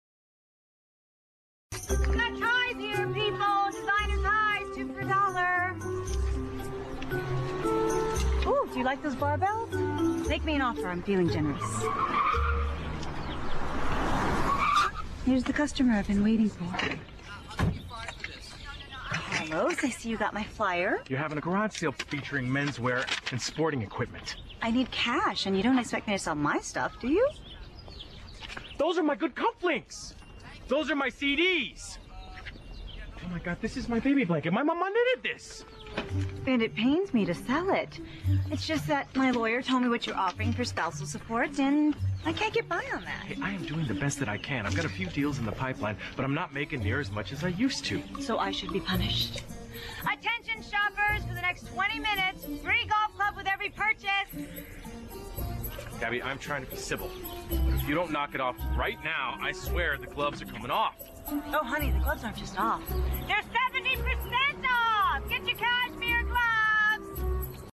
在线英语听力室影视剧中的职场美语 第85期:销售策略的听力文件下载,《影视中的职场美语》收录了工作沟通，办公室生活，商务贸易等方面的情景对话。每期除了精彩的影视剧对白，还附有主题句型。